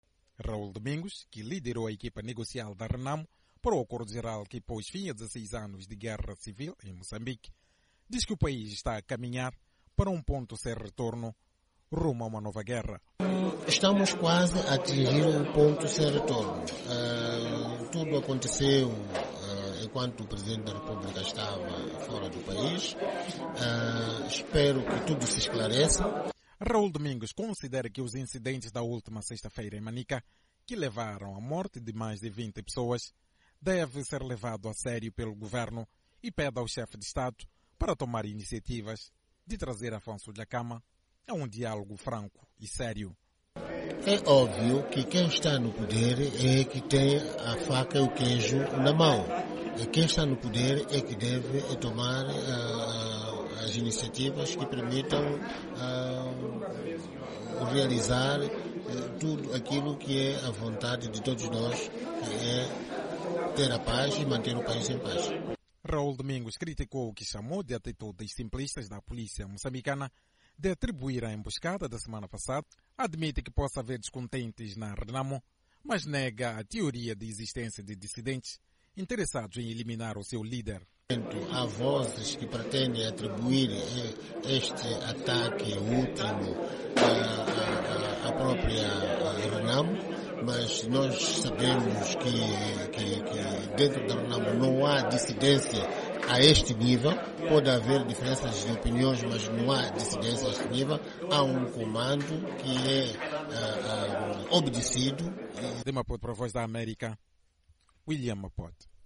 O antigo braço direito do líder da Renamo, diz que Moçambique está prestes a atingir um ponto sem retorno para a guerra e exortou o presidente Nyusi a tomar a iniciativa para inverter a situação. Numa entrevista a VOA, Raul Domingos desmentiu a tese das autoridades, segundo as quais, homens da...